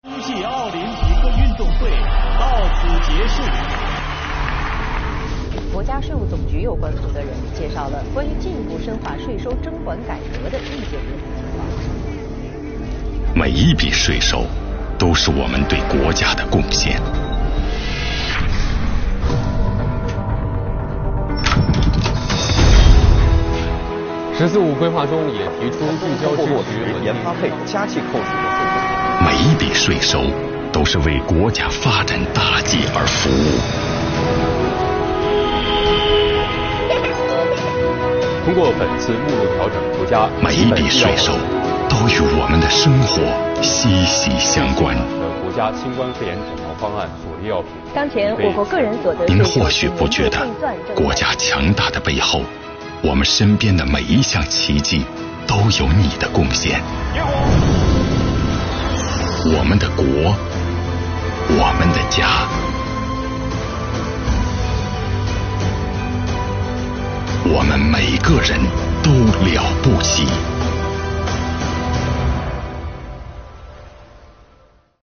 标题: 公益广告 | 我们每个人都了不起